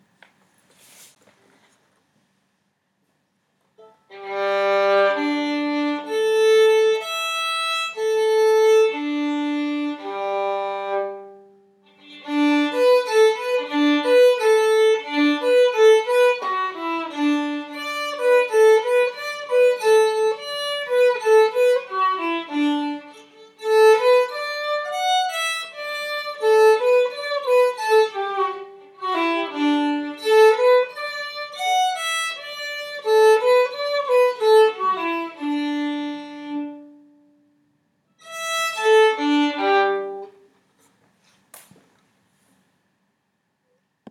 I did a little tune with the Pro arte right before i switched it to zyex, then recorded with zyex. keep in mind the zyex were still stretching and i wasn't originally intending to post this on here, because it was just so i could hear how they differed. i can say i think i like the way zyex seems to tone down a bit of the loudness. Though that could just be how i was standing with my violin near my computer I think the zyex are a tad bit warmer (?) sounding and the pro arte are a bit more bright.
Zyex-strings.mp3